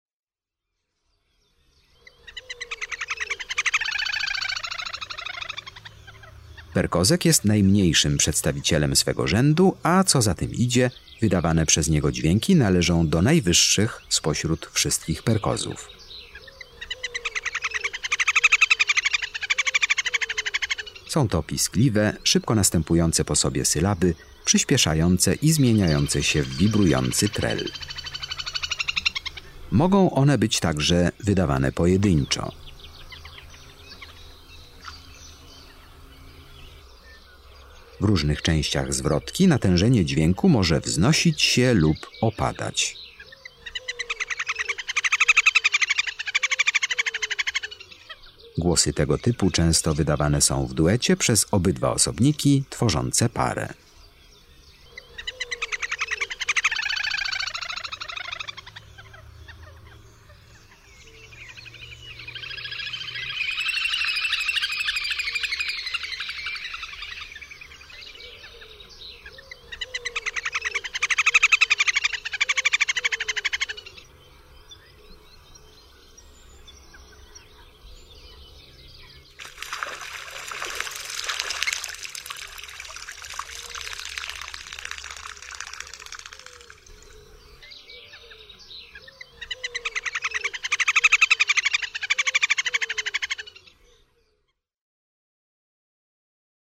33 PERKOZEK.mp3